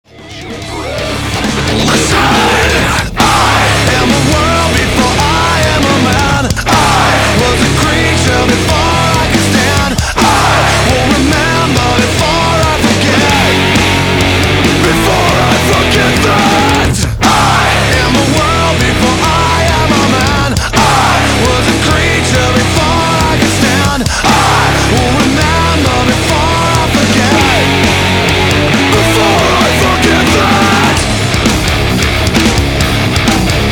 громкие
жесткие
брутальные
Драйвовые
nu metal
Жанр: Death Metal